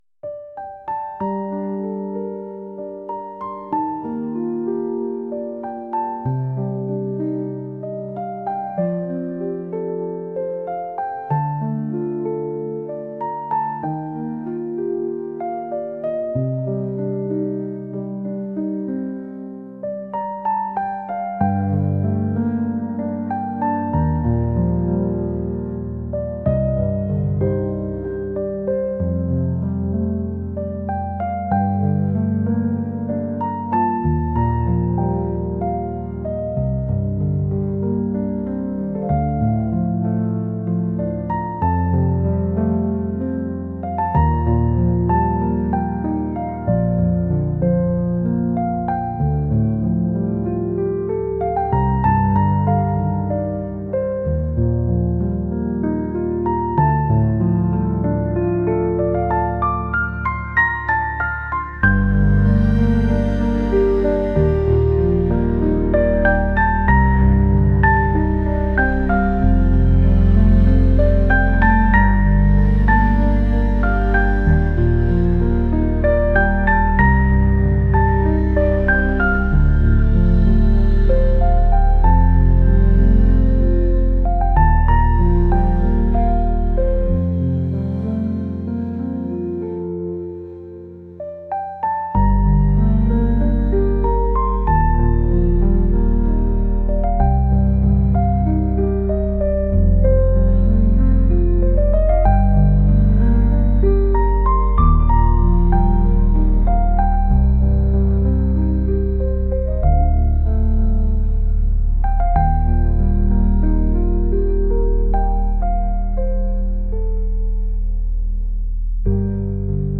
ethereal | classical